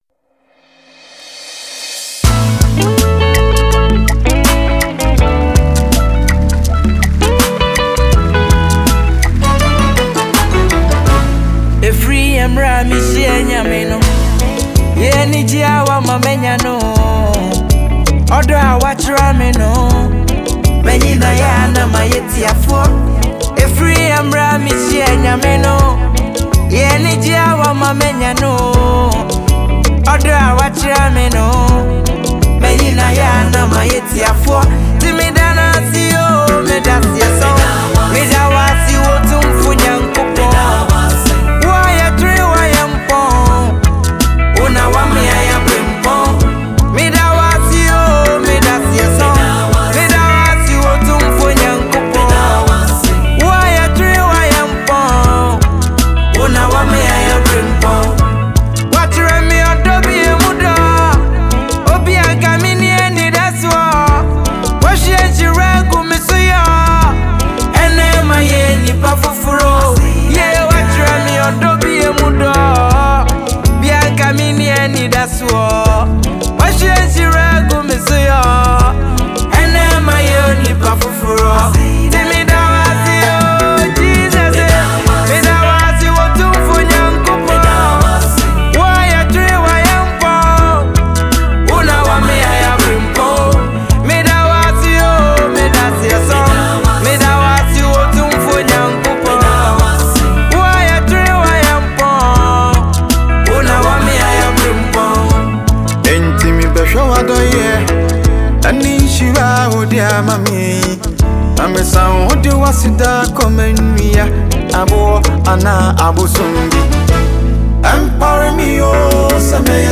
GospelMusic